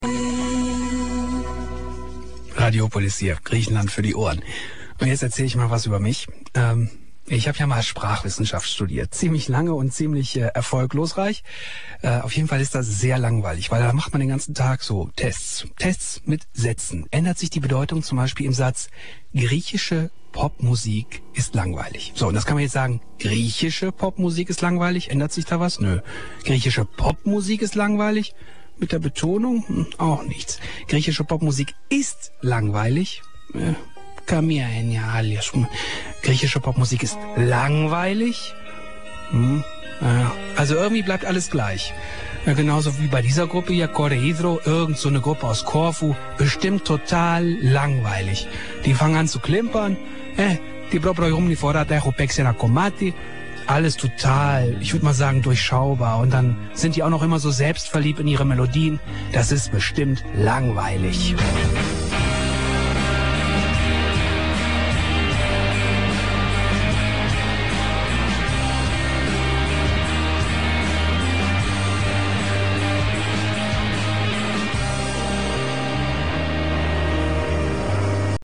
απόσπασμα [1.2 MB] σχετικό με τους Κόρε. Ύδρο. από το ραδιοφωνικό πρόγραμμα RADIOPOLIS (27.01.07) του WDR-FUNKHAUS EUROPA COLOGNE / RADIO MULTIKULTI BERLIN